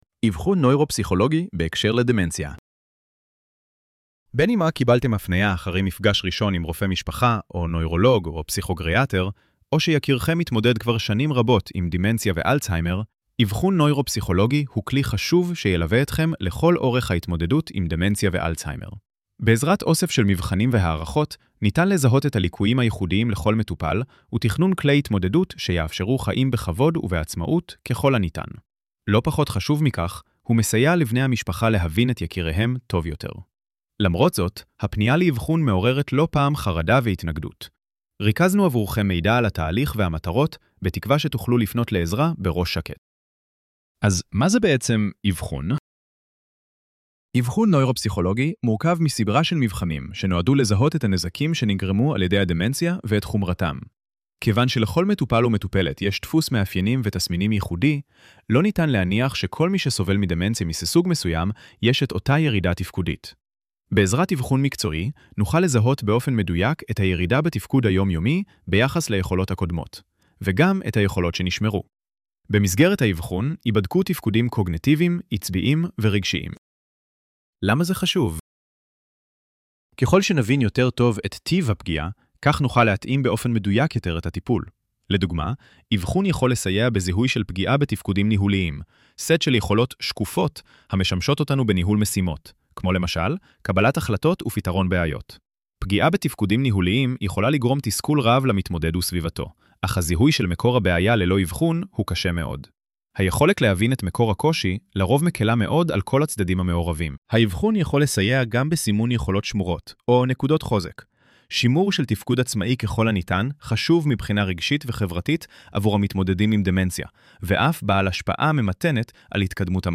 ElevenLabs_אבחון_נוירופסיכולוגי_בהקשר_לדמנציה.mp3